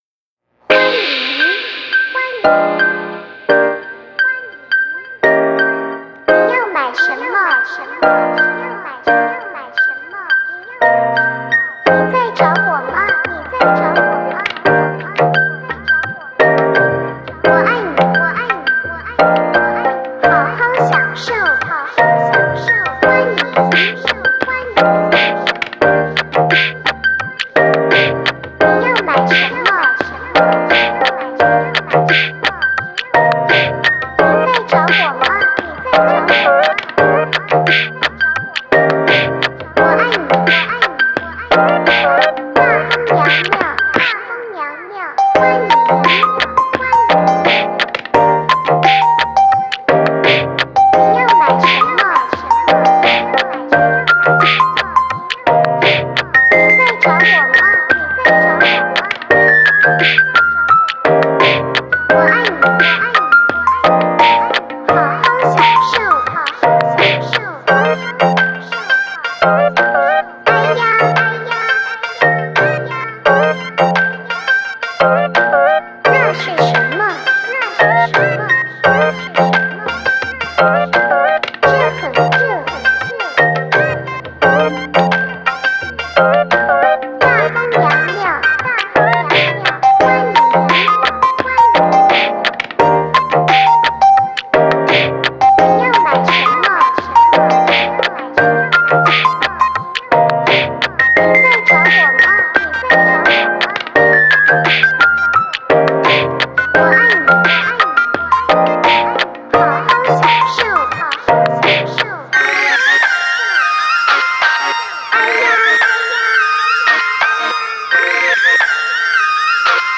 ●Atmosphere:Relaxing Chinese BGM
■Instruments:Beatbox, Bass, Synth, Chinese
AttributesHappy Beautiful Peaceful
GenrePop EasyListening Acoustic